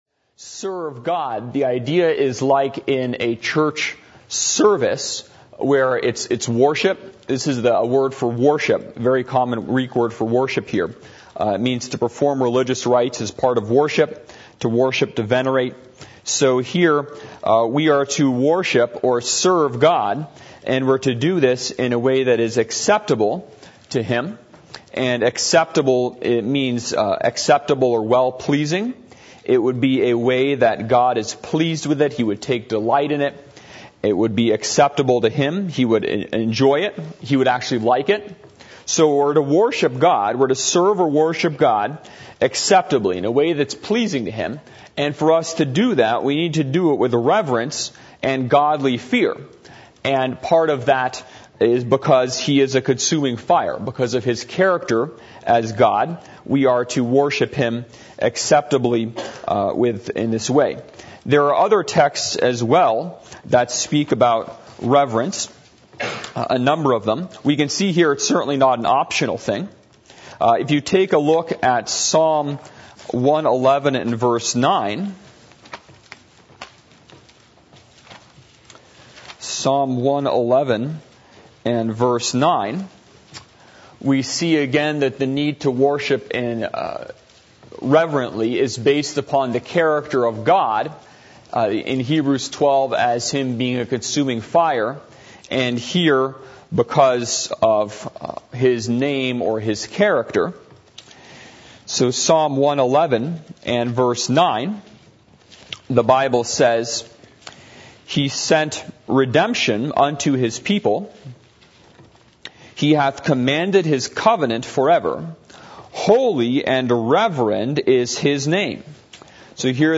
Hebrews 12:28-29 Service Type: Sunday Evening %todo_render% « What Think Ye Of Christ?